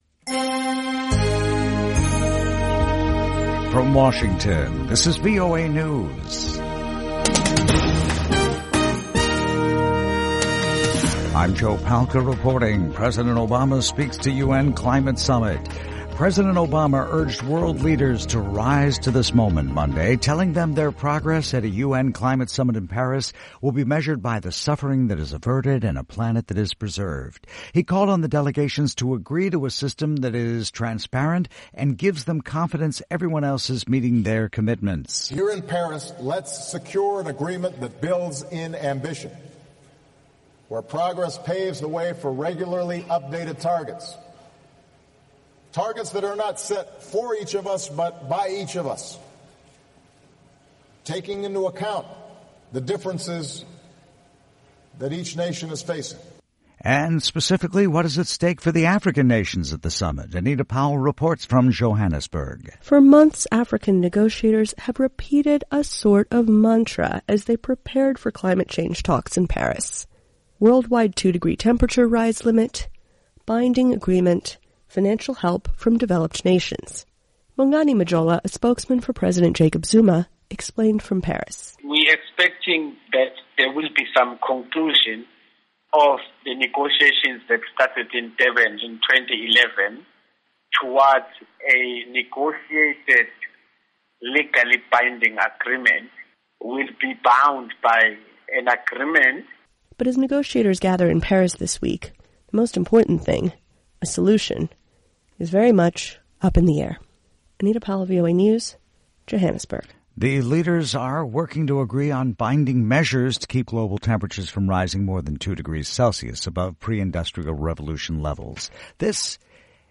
VOA English Newscast 1600 UTC November 30, 2015